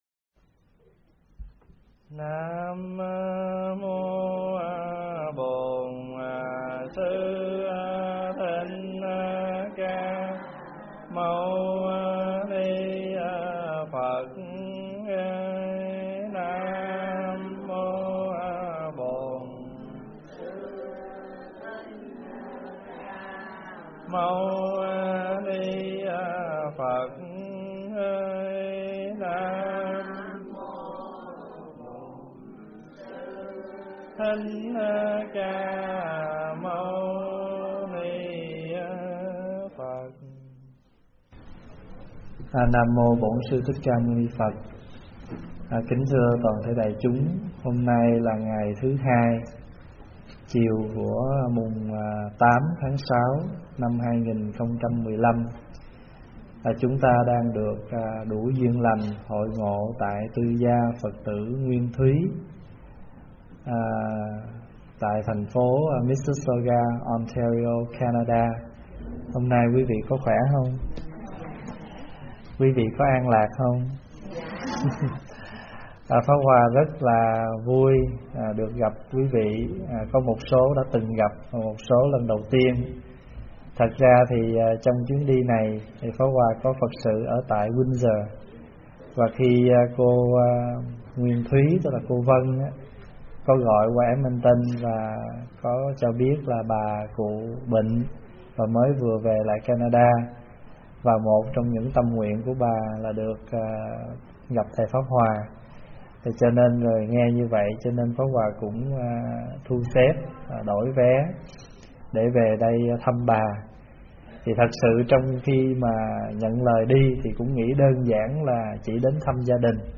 thuyết pháp Sợ Để Tu